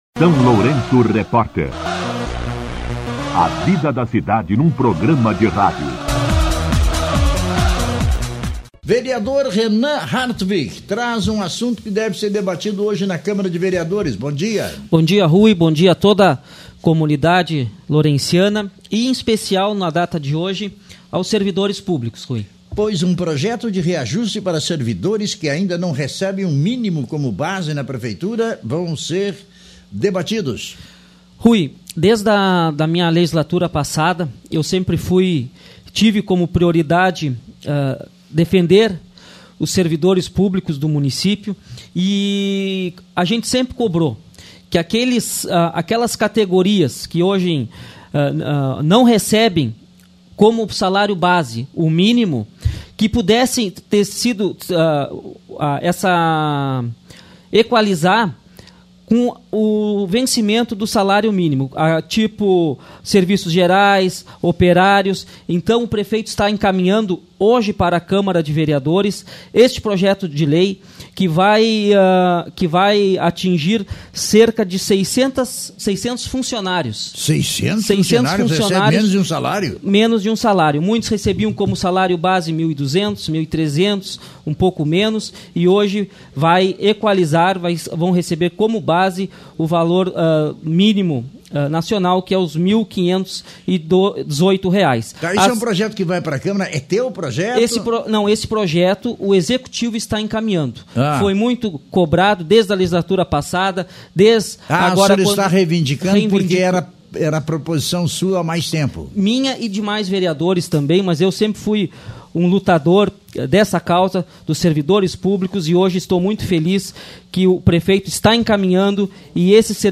O vereador Renan Hartwig (Progressistas) participou do programa SLR RÁDIO nesta segunda-feira (14) para comentar o Projeto de Lei encaminhado pelo Executivo Municipal, que prevê o reajuste dos salários de servidores que ainda recebem abaixo do salário mínimo como base.
Entrevista com O vereador Renan Hartwig (Progressistas)